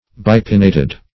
Search Result for " bipinnated" : The Collaborative International Dictionary of English v.0.48: Bipinnate \Bi*pin"nate\, Bipinnated \Bi*pin"na*ted\, a. [Pref. bi- + pinnate; cf. F. bipinn['e].
bipinnated.mp3